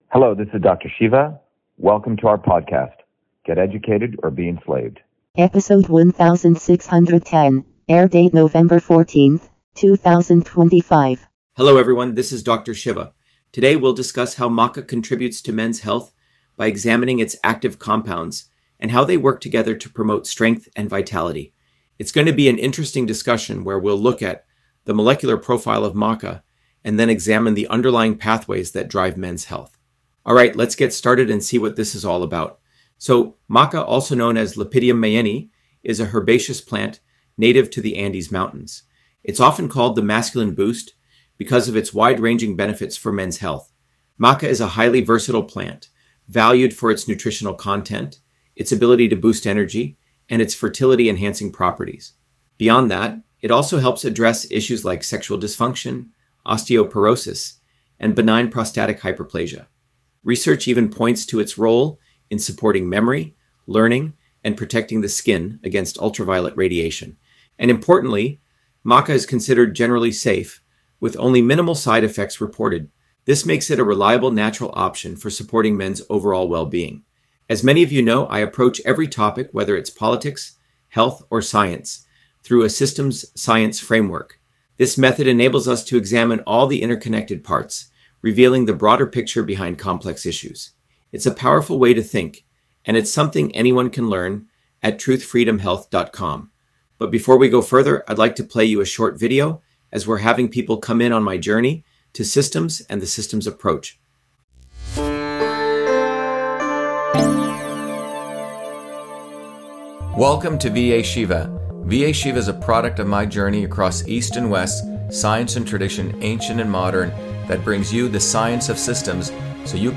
In this interview, Dr.SHIVA Ayyadurai, MIT PhD, Inventor of Email, Scientist, Engineer and Candidate for President, Talks about Maca on Men’s Health: A Whole Systems Approach